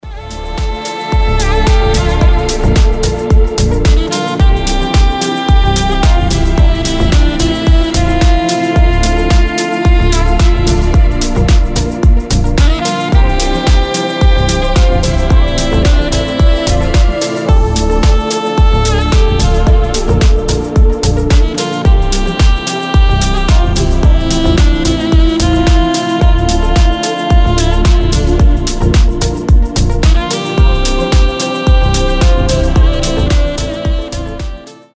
• Качество: 320, Stereo
deep house
без слов
красивая мелодия
Саксофон
Очаровательная музыка саксофона